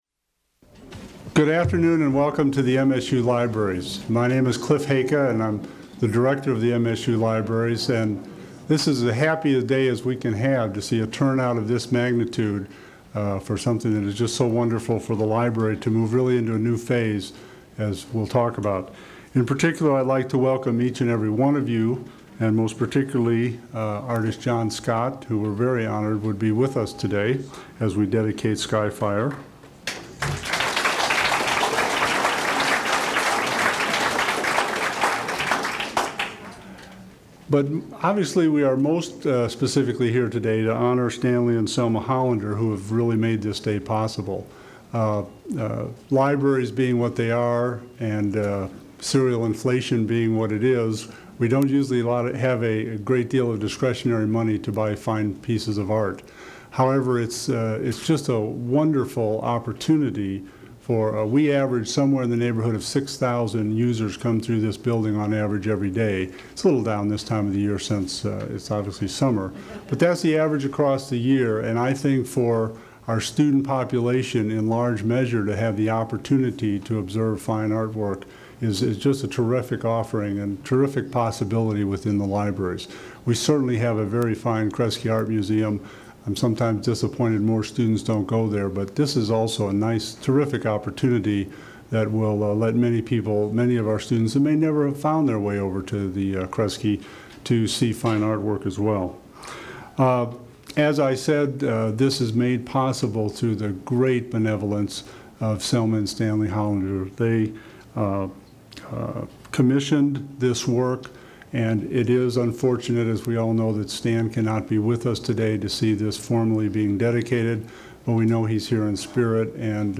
Dedication ceremony of the sculpture "Skyfire" held at the Michigan State University Main Library